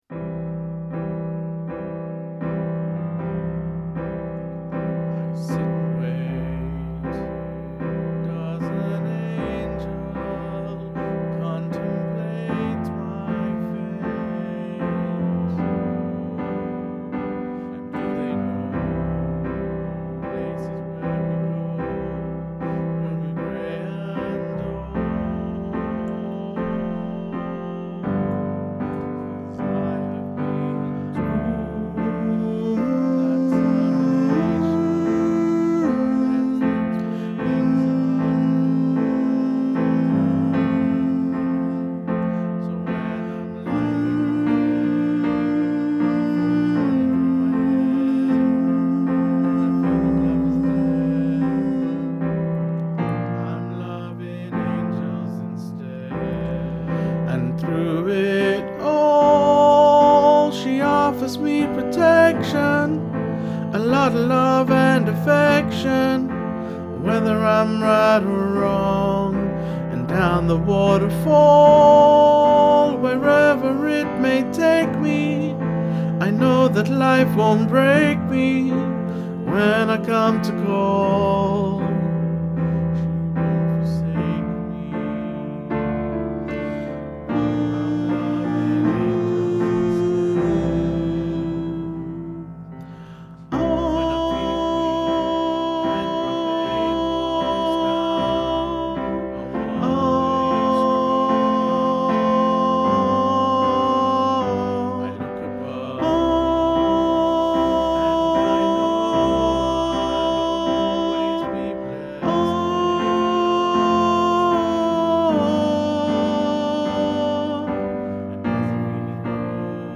angels tenor 1 - Rame Peninsula Male Voice Choir
angels tenor 1